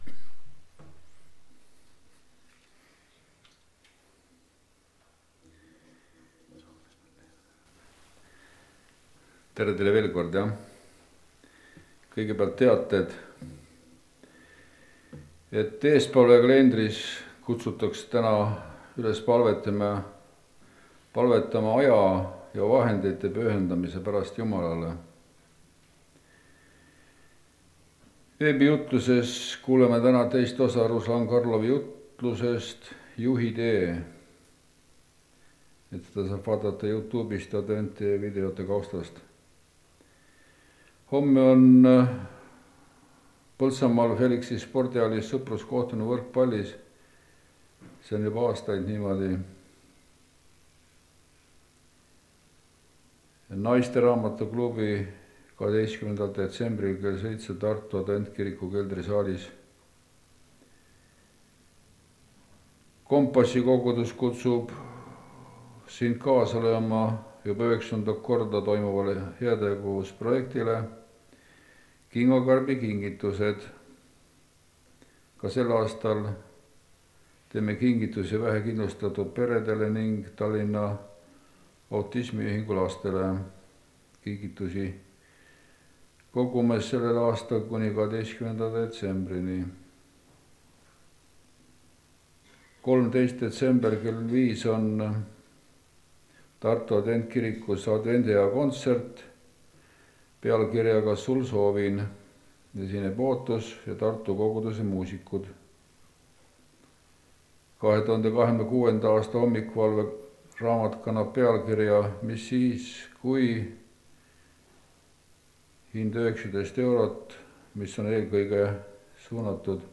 Õhtusöök Jeesusega (Rakveres)
Koosolekute helisalvestused